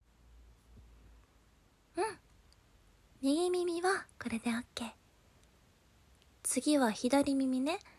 疲れたあなたを、優しい彼女が膝枕で耳かきしながらとことん甘やかすシチュエーションボイスです。
耳を優しくなでる音、甘い囁き、心地よい吐息で、あなたの心と体を癒やします。